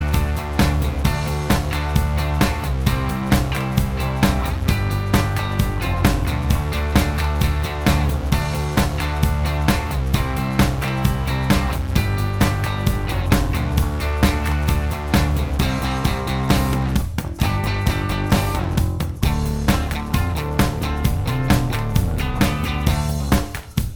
Minus Lead Guitar And Solo Rock 4:04 Buy £1.50